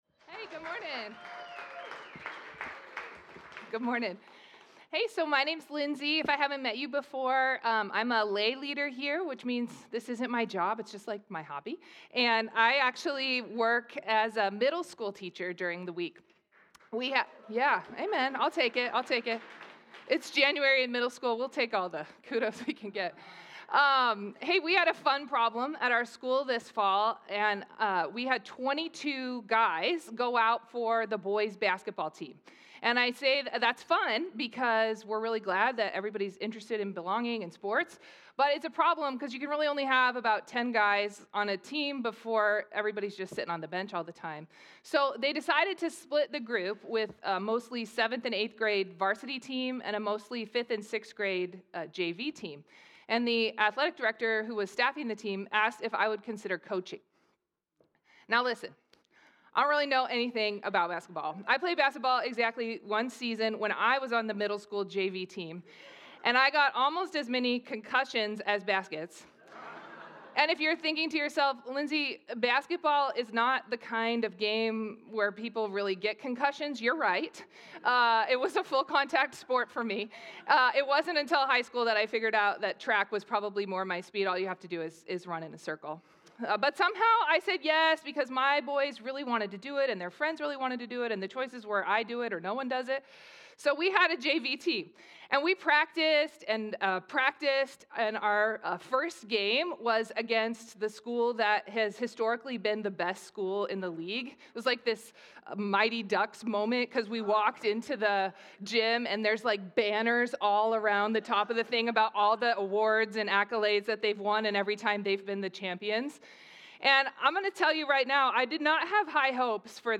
The River Church Community Sermons Not The Way I Expected